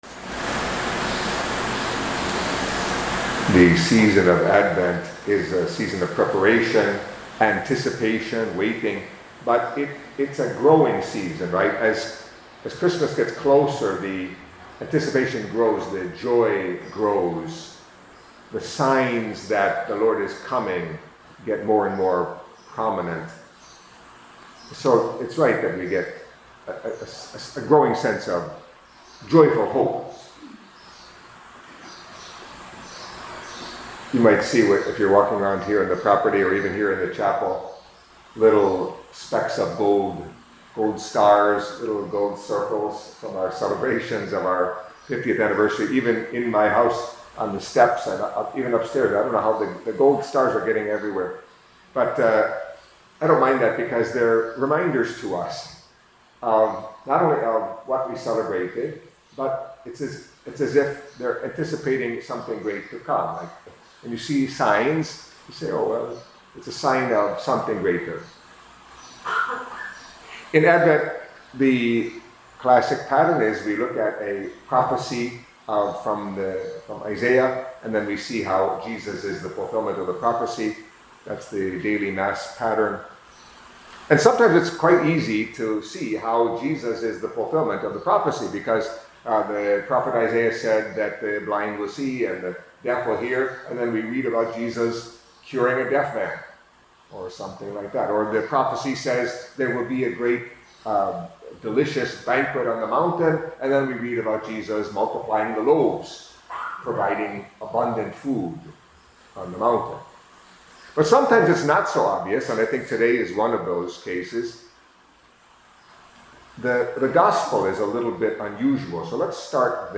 Catholic Mass homily for Thursday of the Second Week of Advent